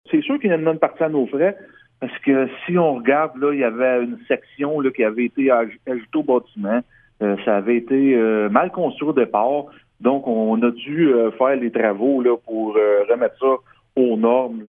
L’incendie a permis de révéler des défauts de fabrication dans une section qui avait été ajoutée au bâtiment. Le maire de Gracefield, Mathieu Caron, explique de quoi il s’agit :